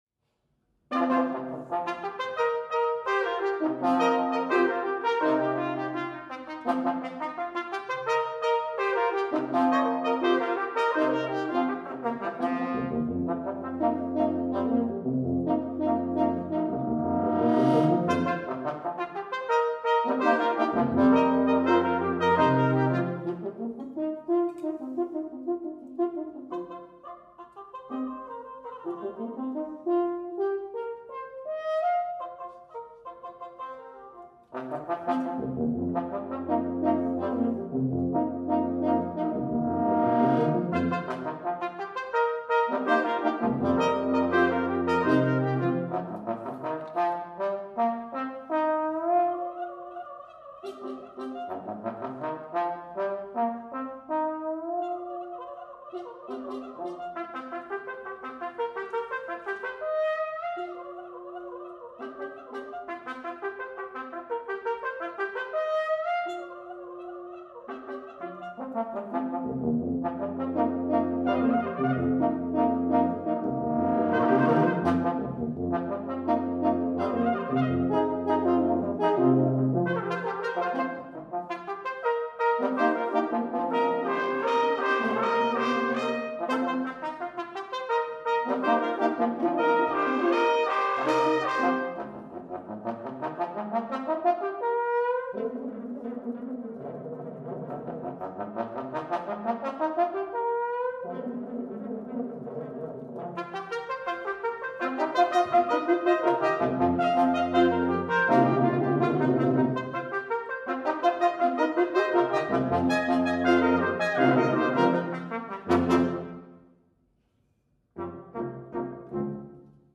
for Brass Quintet (1989)